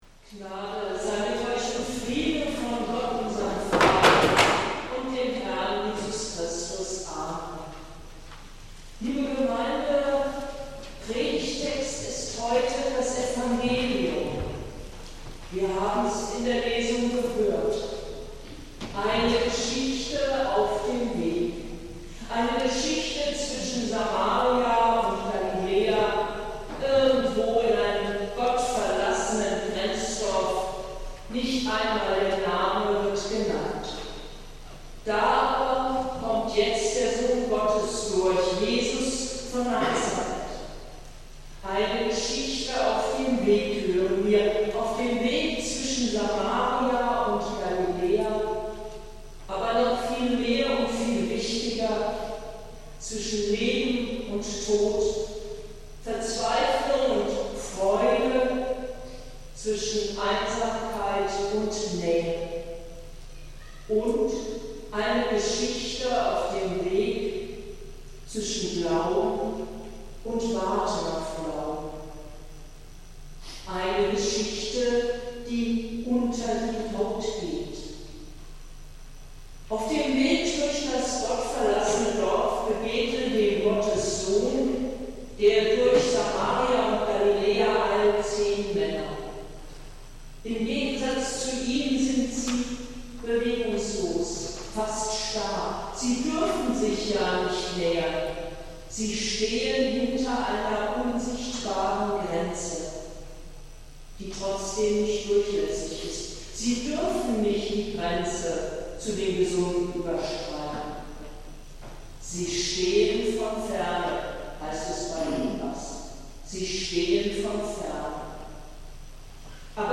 Predigt des Gottesdienstes aus der Zionskirche am Sonntag, den 10. September 2023